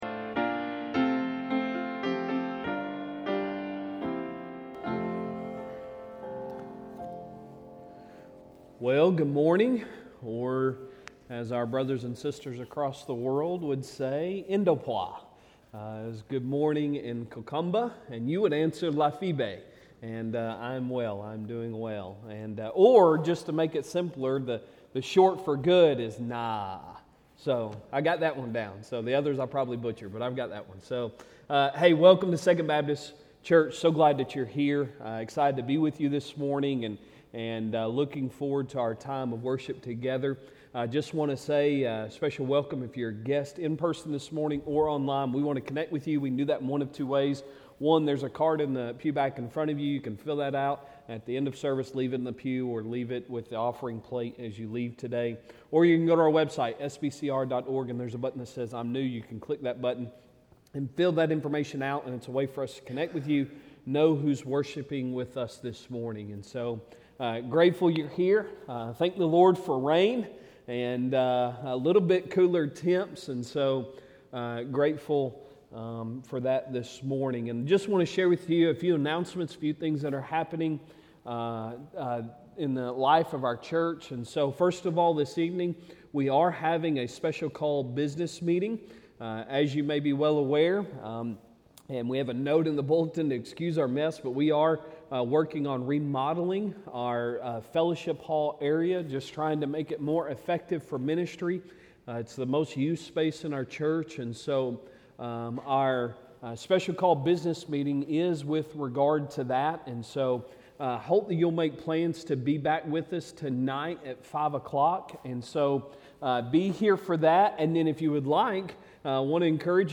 Sunday Sermon July 9, 2023